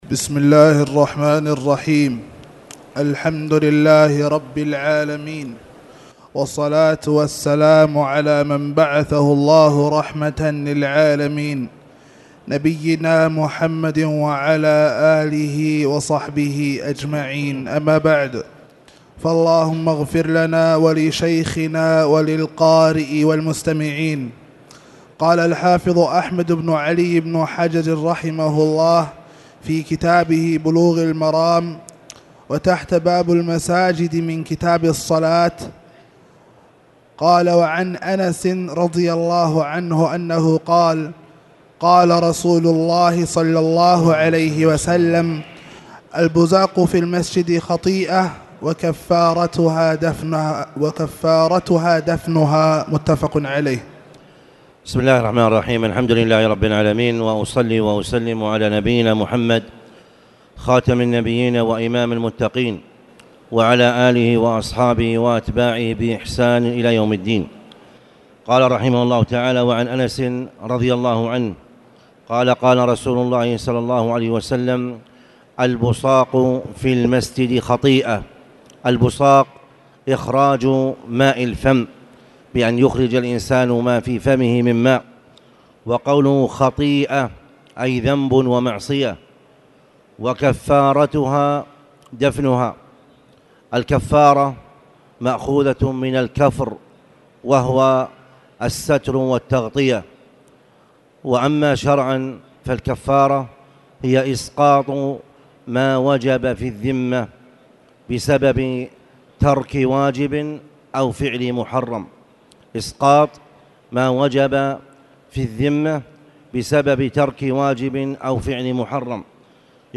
تاريخ النشر ٥ رجب ١٤٣٨ هـ المكان: المسجد الحرام الشيخ